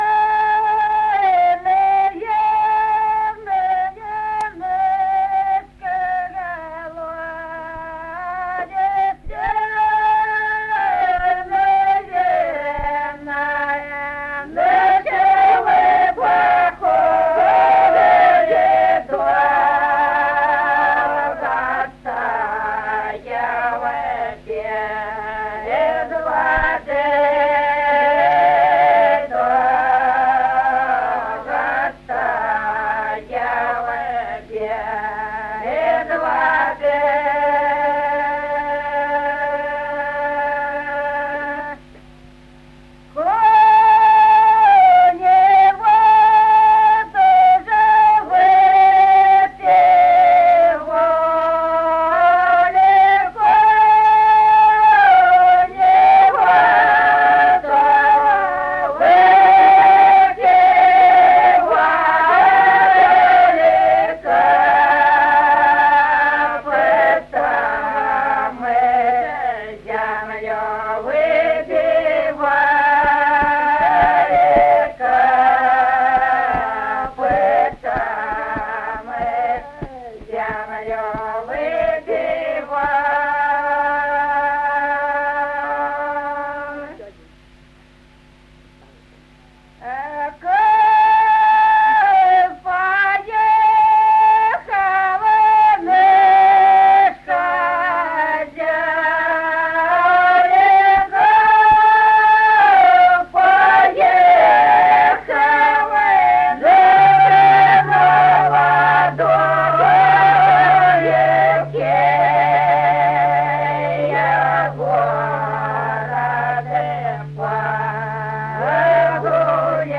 Песни села Остроглядово. Верный наш колодезь.